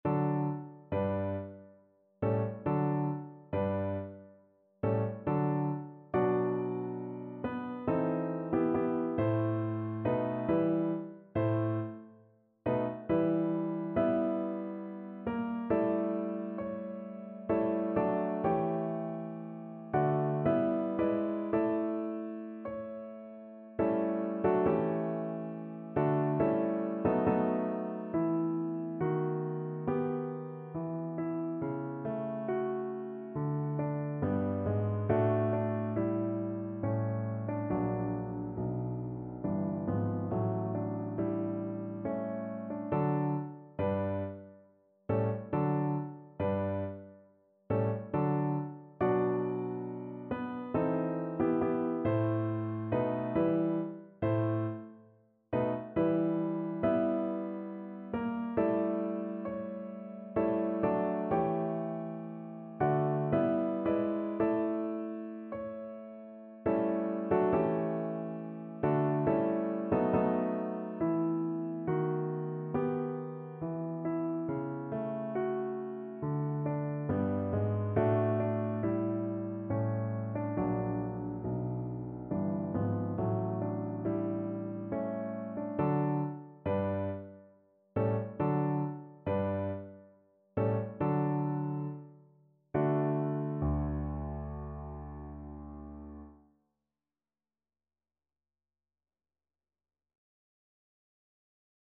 3/4 (View more 3/4 Music)
Larghetto =69
D major (Sounding Pitch) (View more D major Music for Violin )
Classical (View more Classical Violin Music)